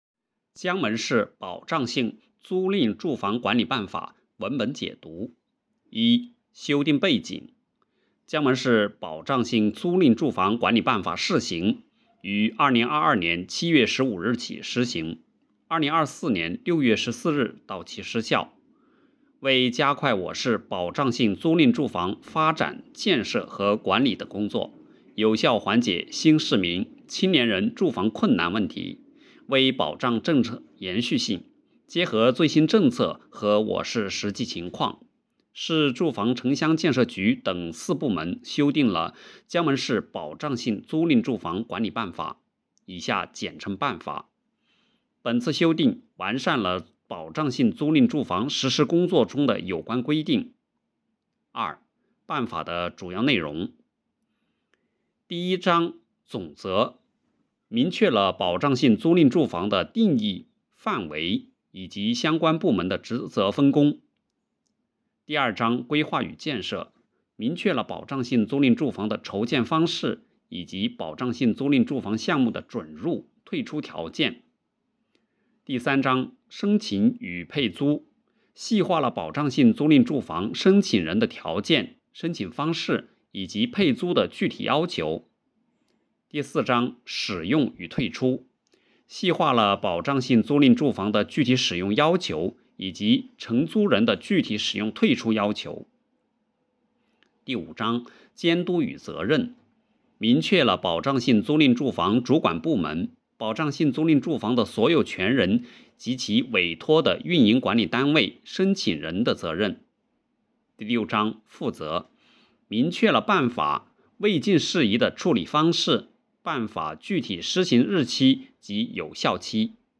音频解读：